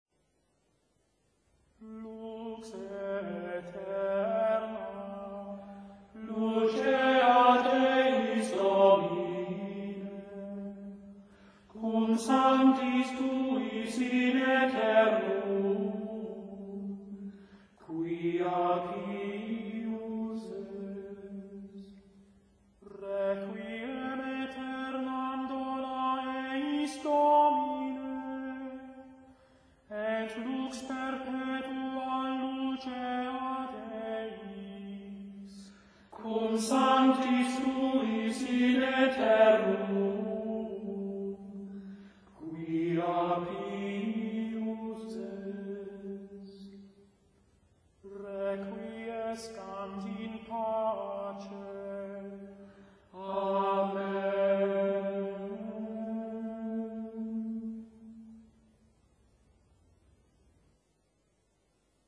Григорианское пение: сборники